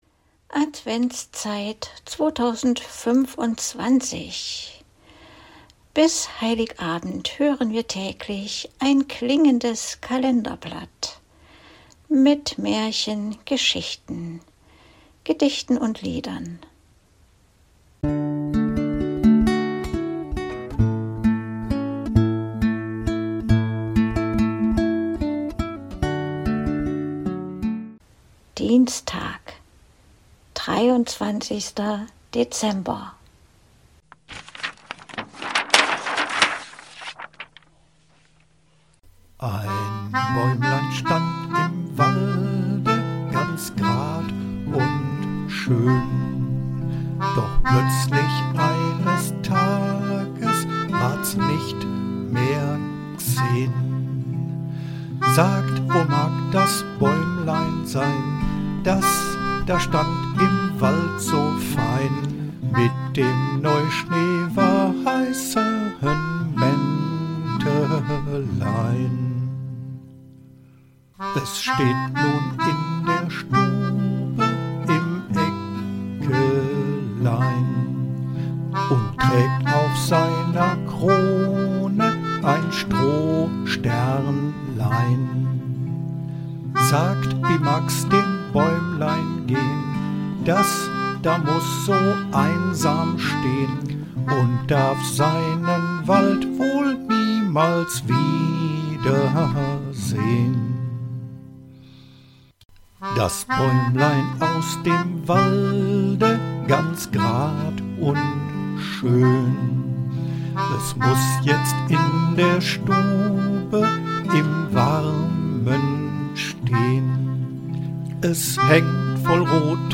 liest ihre selbst verfasste Geschichte